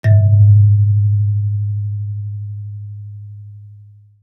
kalimba_bass-G#1-mf.wav